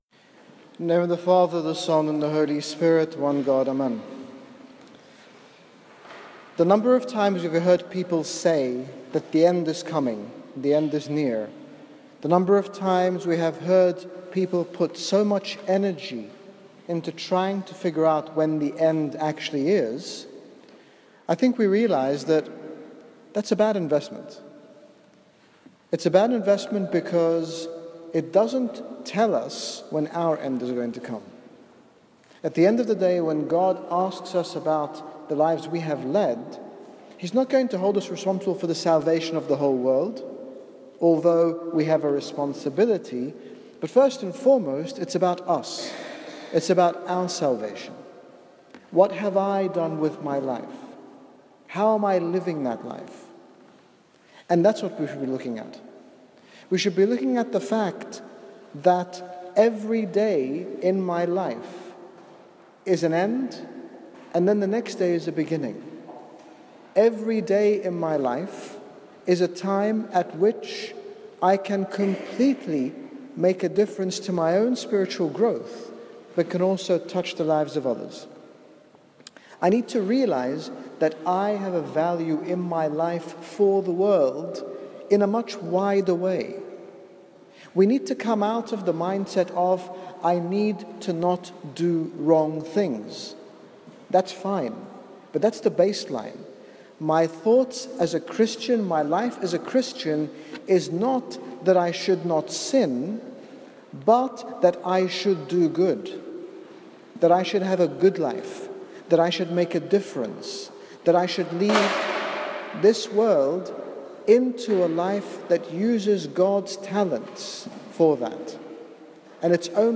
In this short sermon given at St Paul Ministry, His Grace Bishop Angaelos, General Bishop of the Coptic Orthodox Church in the United Kingdom, speaks about the danger of focusing on our faults, sins and failures. His Grace speaks about using our gifts to make a difference in the world, and to live life fully, not in fear.